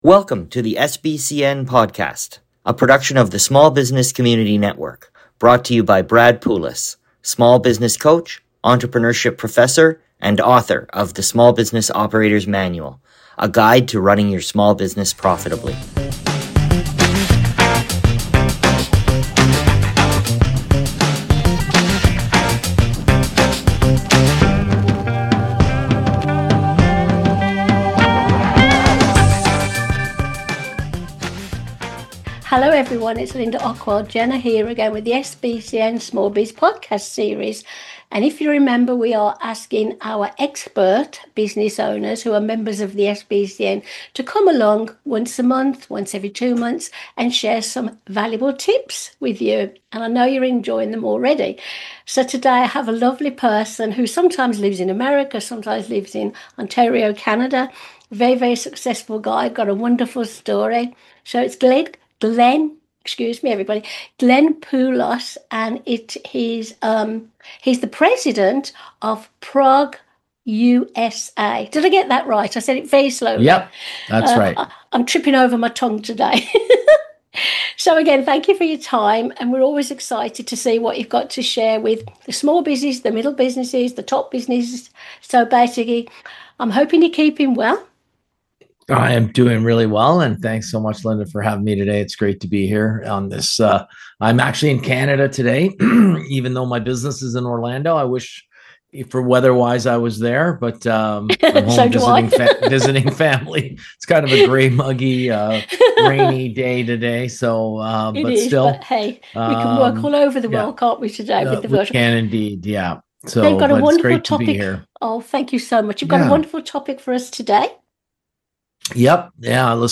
Featuring interviews with CEOs and other small business leaders, the latest new trends and tips for your successful business.